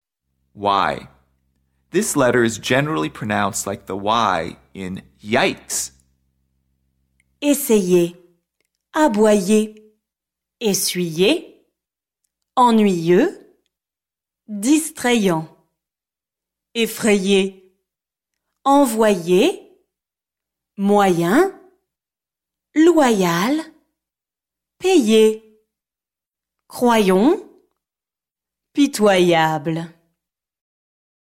y – This letter is generally pronounced like the “y” in “Yikes!”